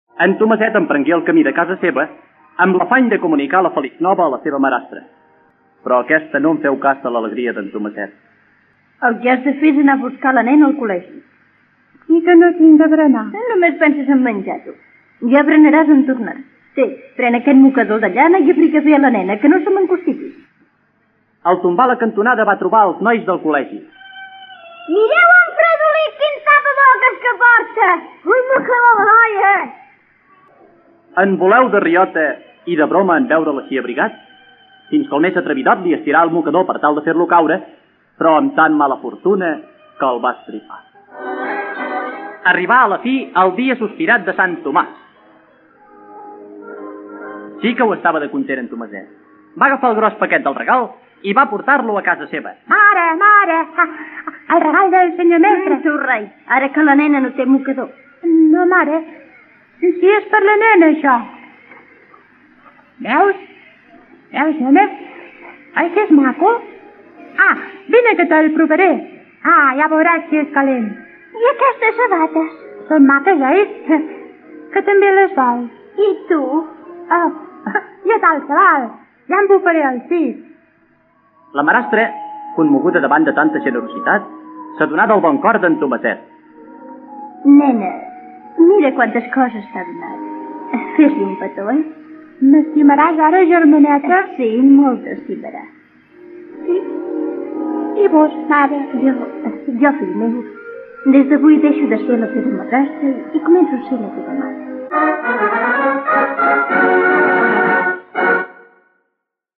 Conte